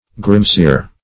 grimsir - definition of grimsir - synonyms, pronunciation, spelling from Free Dictionary Search Result for " grimsir" : The Collaborative International Dictionary of English v.0.48: Grimsir \Grim"sir\, n. A stern man.